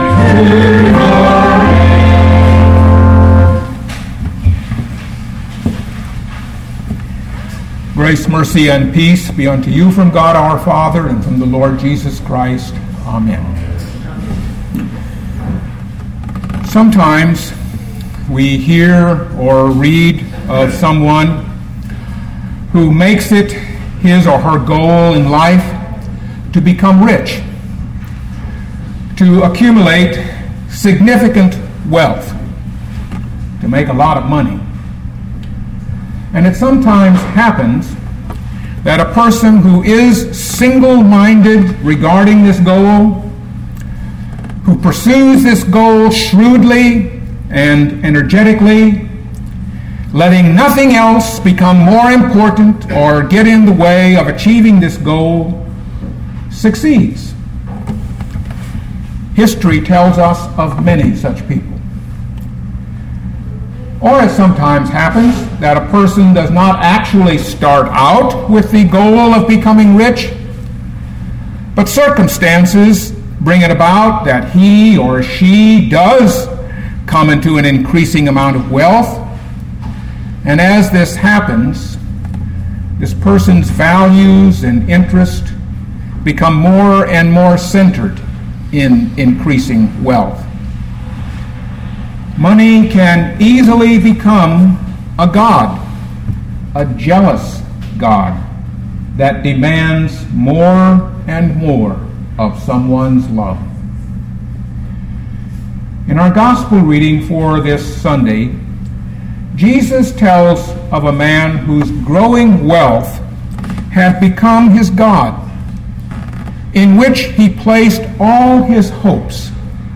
2013 Luke 12:13-21 Listen to the sermon with the player below, or, download the audio.